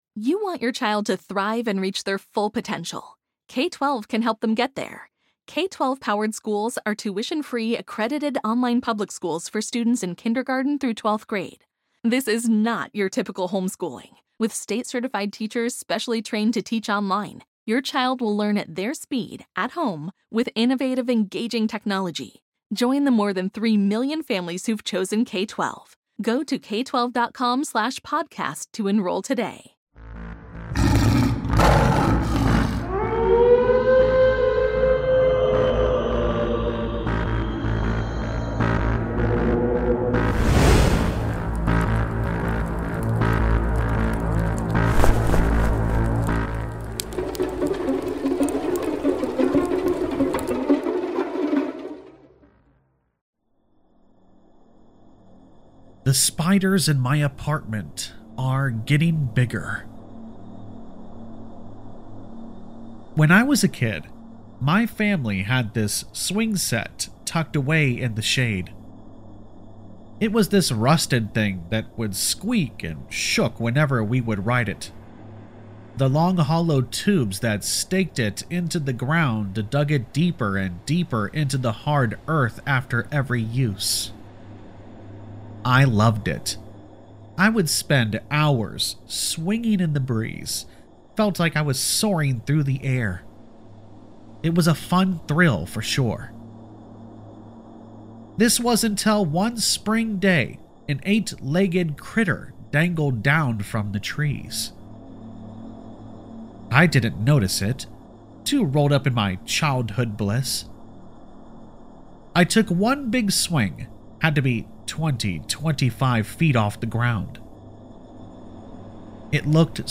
The Spiders in My Apartment Keep Getting Bigger | Creepypasta Horror Story